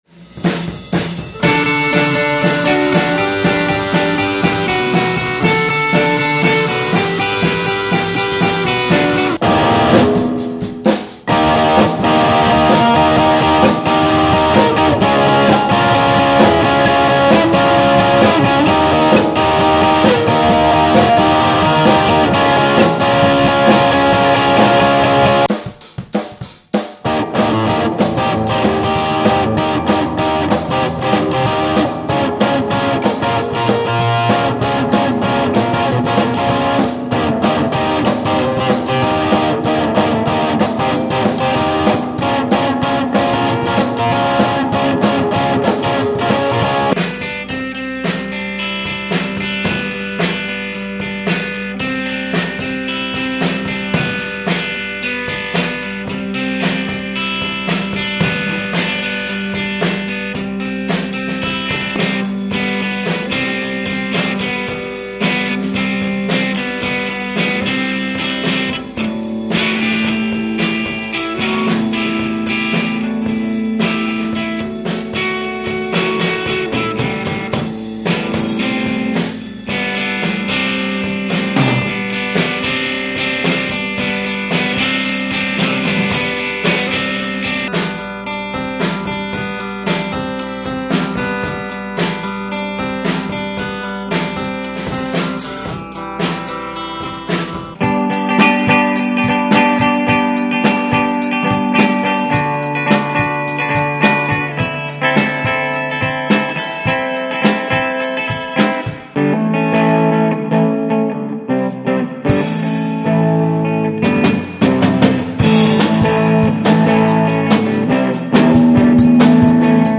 Riffs and grooves